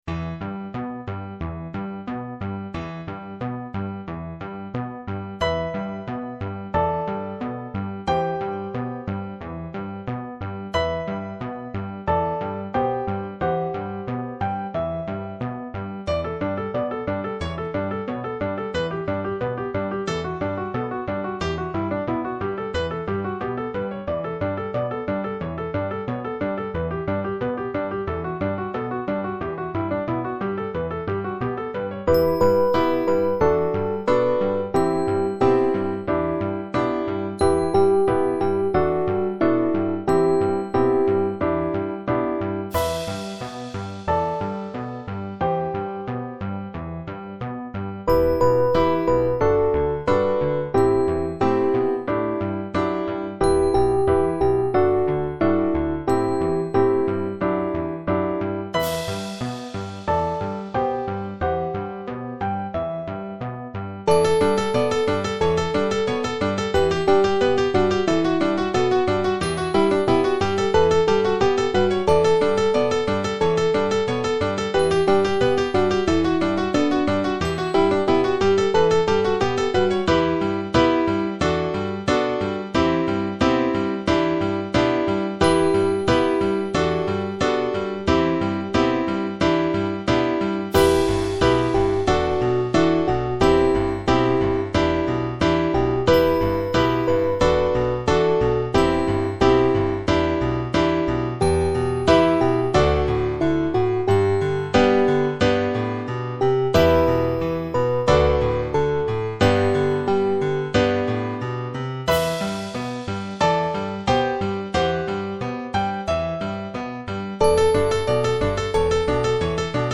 Scarica la base - mp3 2,3 Mb
3 v. e pf.